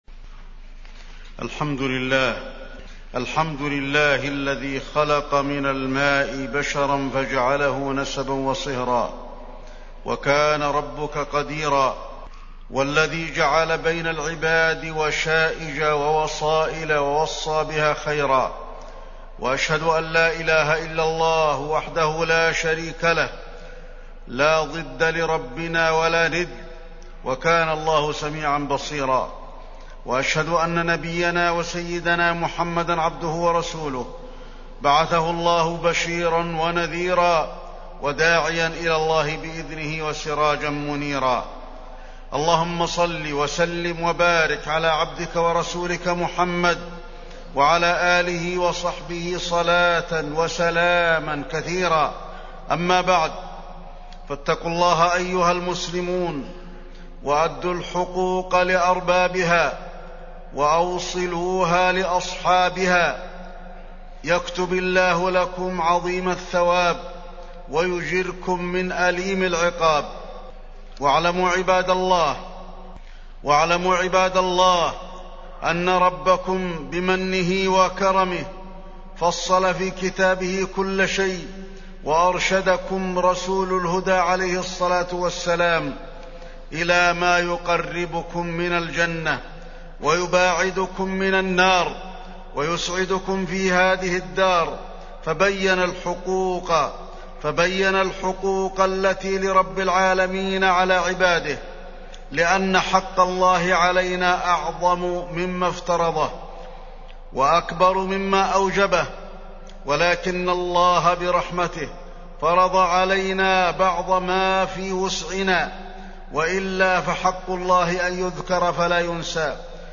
تاريخ النشر ١ جمادى الأولى ١٤٢٨ هـ المكان: المسجد النبوي الشيخ: فضيلة الشيخ د. علي بن عبدالرحمن الحذيفي فضيلة الشيخ د. علي بن عبدالرحمن الحذيفي صلة الرحم